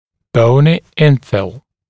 [‘bəunɪ ‘ɪnfɪl][‘боуни ‘инфил]заполнение костной тканью